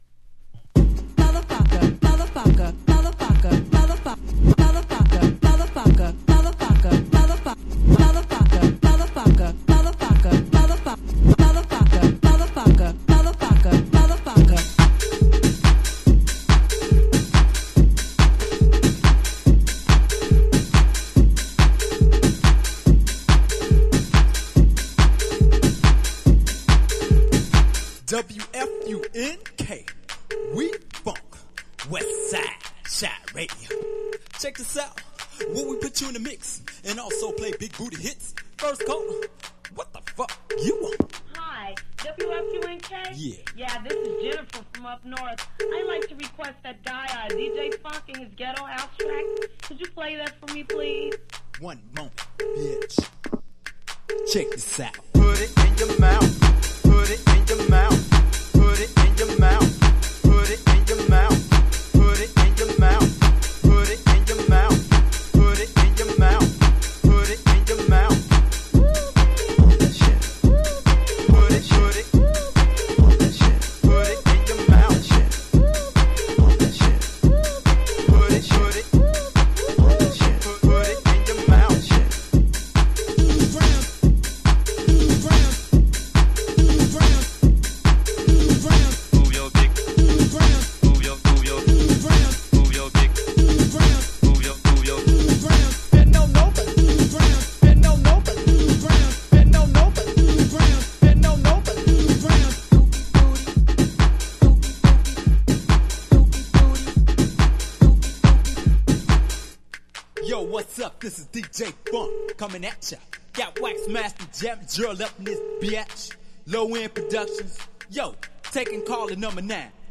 兎にも角にもバウンスバウンス。